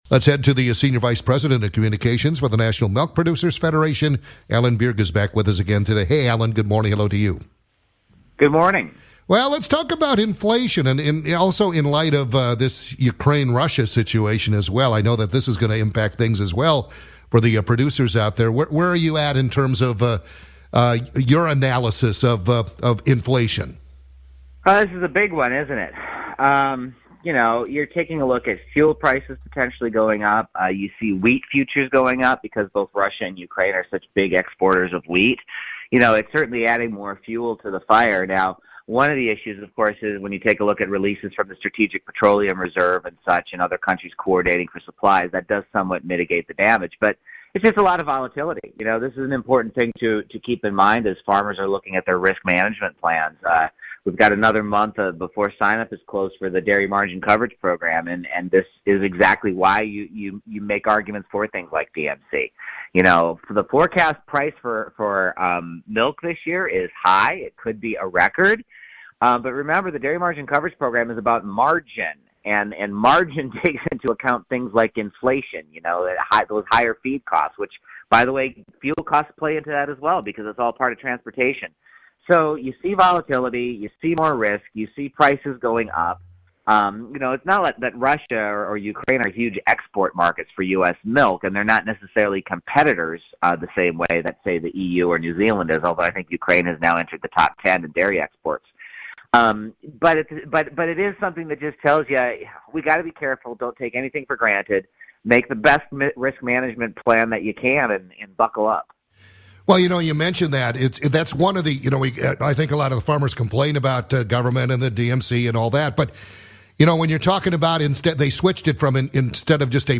in an interview with WEKZ radio, Janesville, Wisconsin.